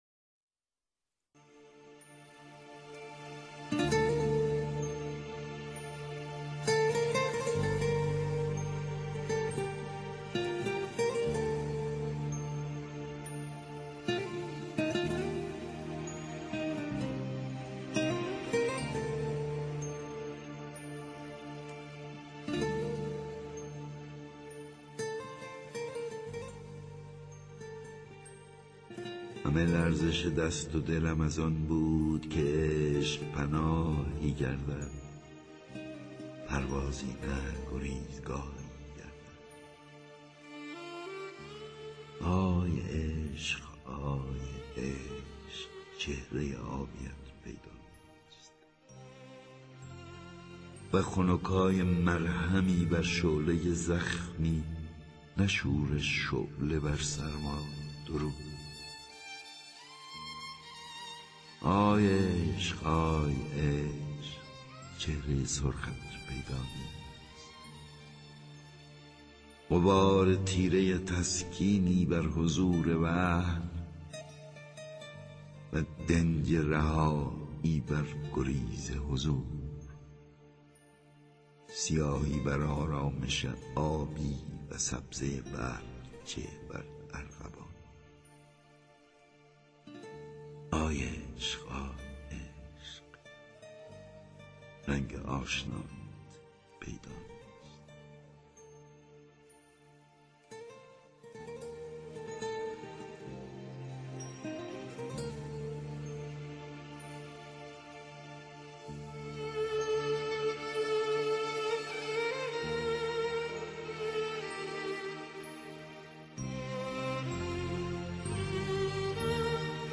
دانلود دکلمه آی عشق! با صدای احمد شاملو
گوینده :   [احمد شاملو]